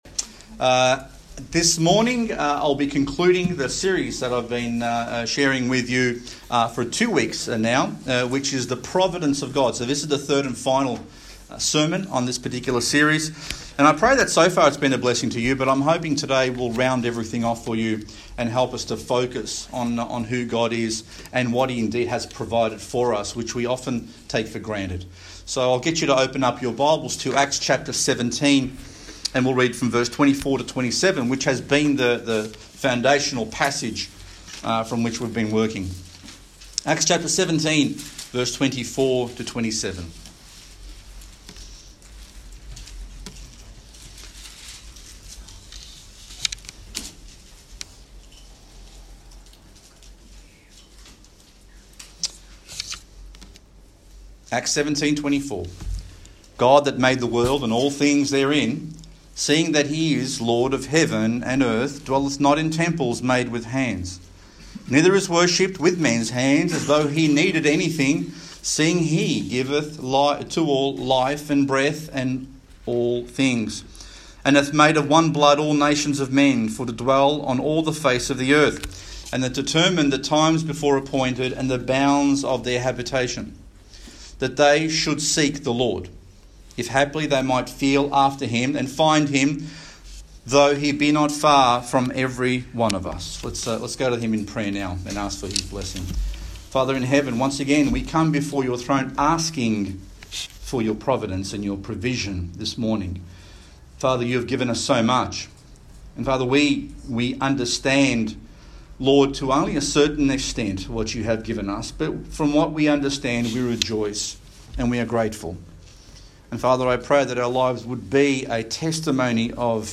If there is any purpose for this series of sermons it would be to help you begin to take a closer look at your life, to open your eyes to the working of God around you, to look back at your life and glorify God for how He brought you to where you are right now.